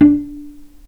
vc_pz-D4-mf.AIF